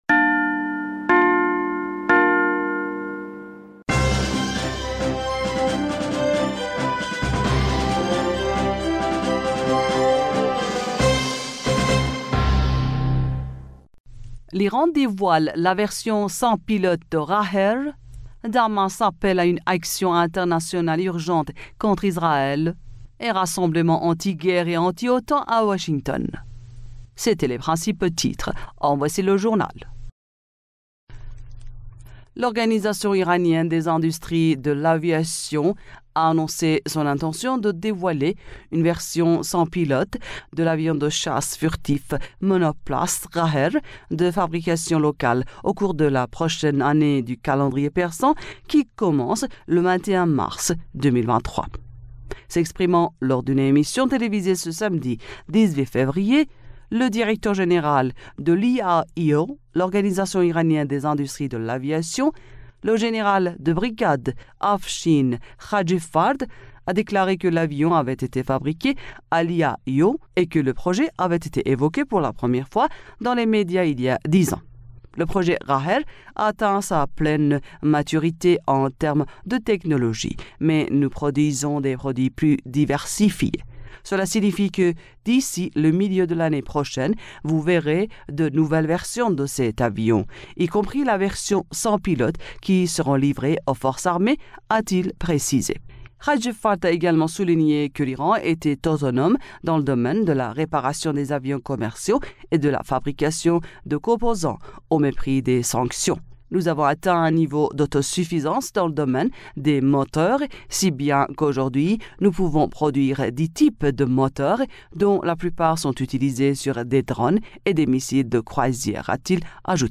bulletin d'information